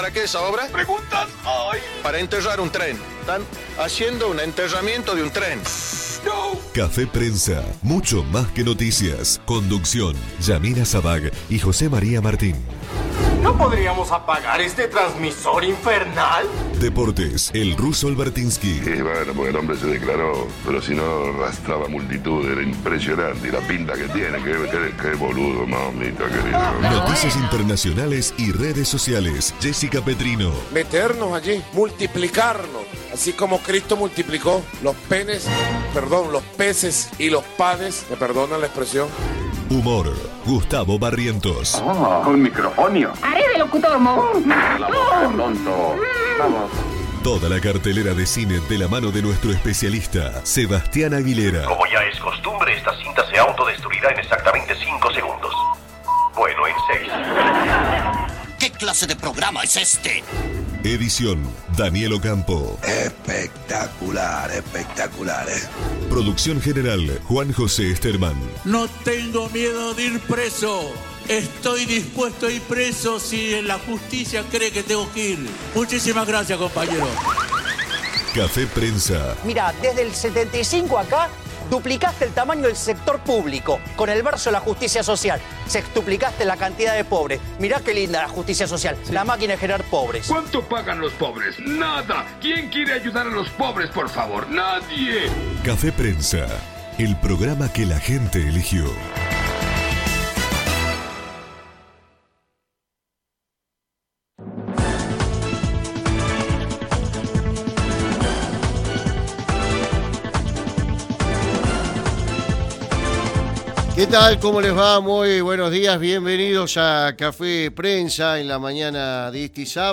El concejal de Fuerza Republicana, Ramiro Ortega, en diálogo con Café Prensa, analizó el panorama electoral provincial y cómo se encuentra su partido en este contexto. Además, se refirió a la visita del diputado nacional Javier Milei.